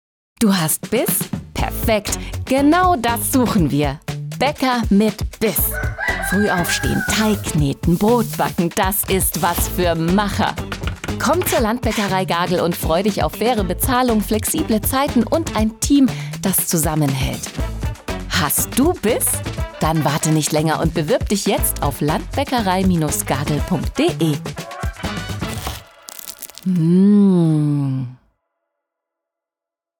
Landbäckerei Gagel Radiospot 2024 Transkription Du hast Biss?
gagel_radiospot_2024_-6dB.mp3